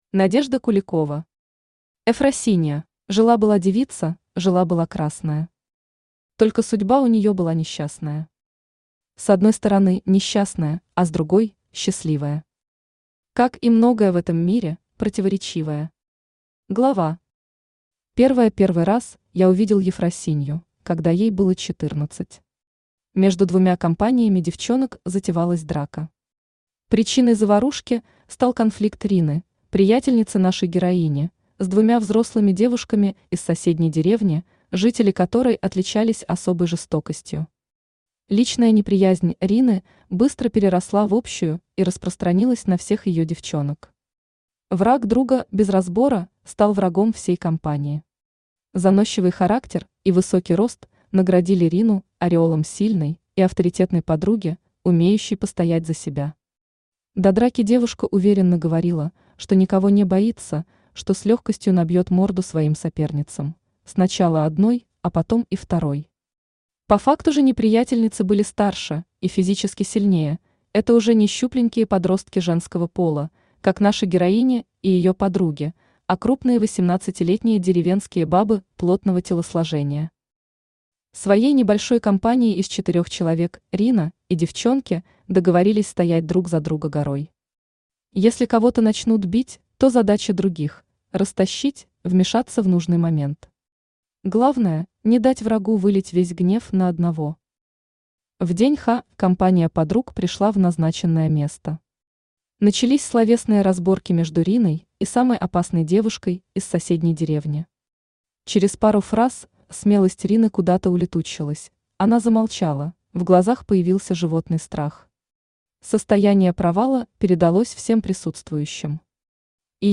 Aудиокнига Efrosinia Автор Надежда Куликова Читает аудиокнигу Авточтец ЛитРес. Прослушать и бесплатно скачать фрагмент аудиокниги